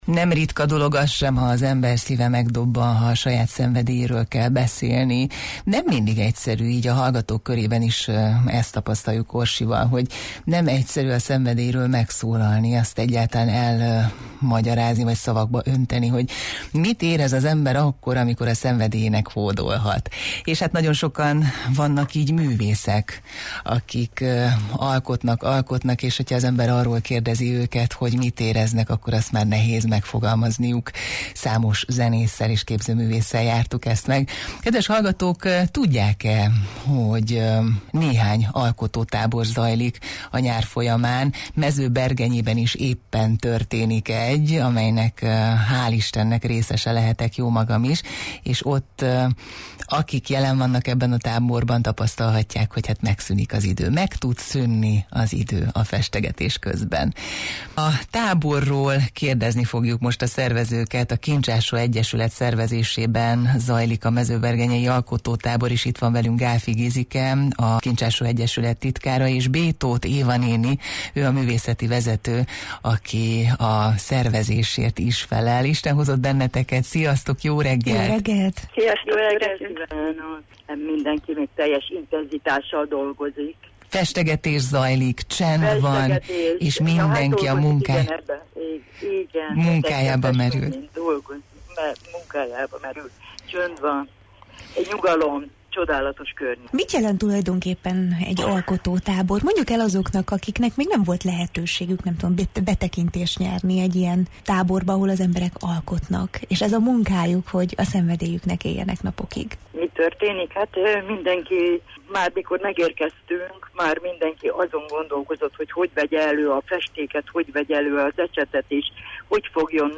beszélgettünk arról, hogy milyen az, amikor az ember úgy él a szenvedélyének, hogy magának sem és másnak sem árt vele, sőt!